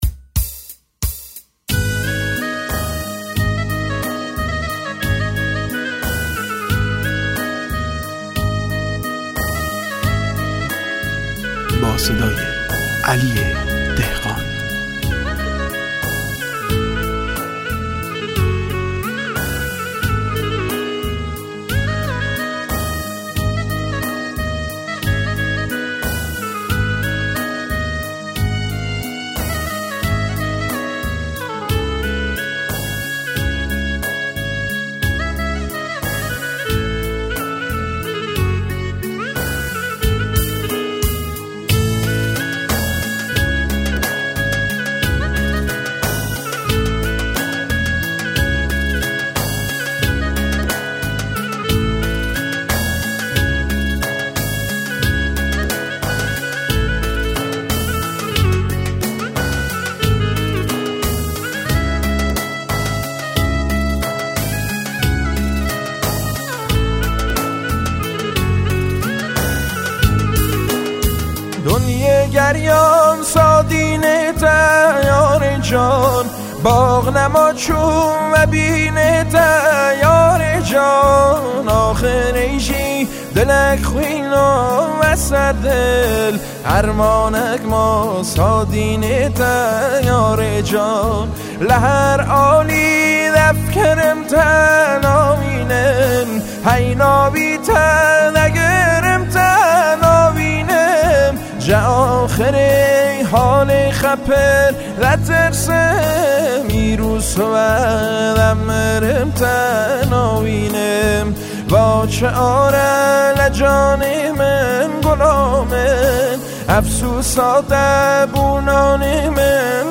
اهنگ کرمانجی